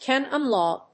cánon láw